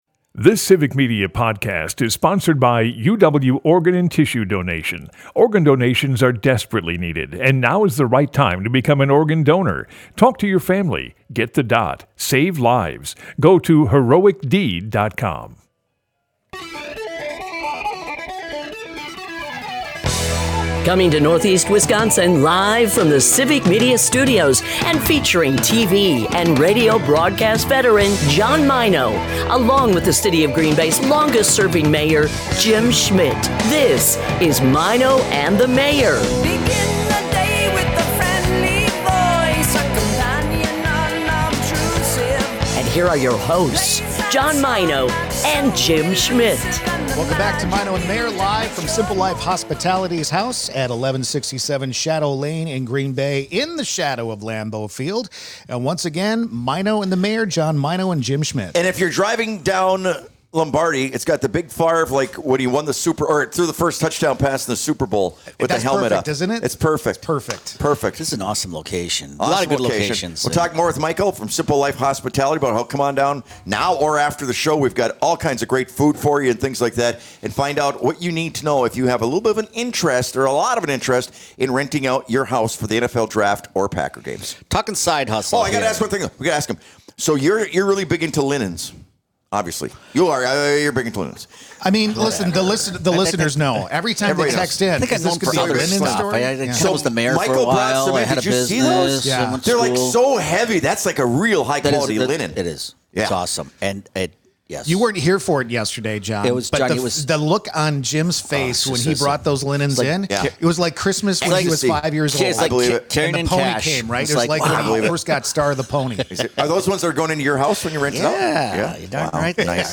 More from the Simple Life Hospitality Party House across from Lambeau Field!